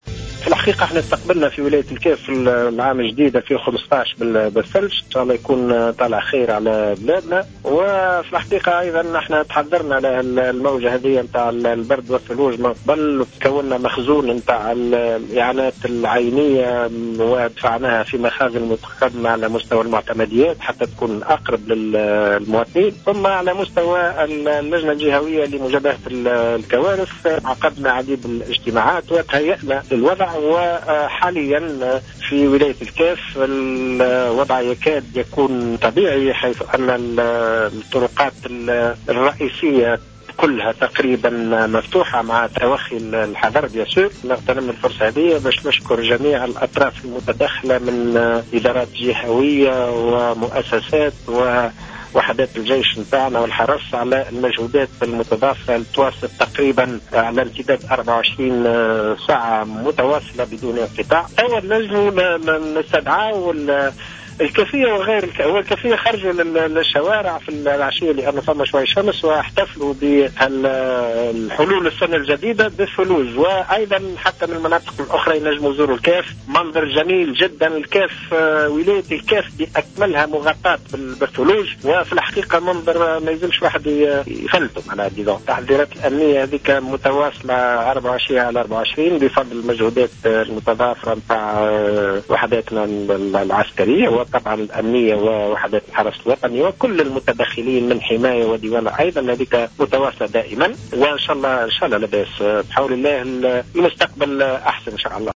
ودعا المطماطي في اتصال هاتفي مع الجوهرة أف أم المواطنين إلى زيارة الكاف و التمّتع بالمناظر الطبيعية الخلابّة وعدم تفويت فرصة مشاهدة الولاية وهي مكسوة بالكامل بالثلوج. من جانب آخر أكد الوالي تواصل الاستعدادات الأمنية تحسبا لأي تحرك إرهابي رغم صعوبة المناخ .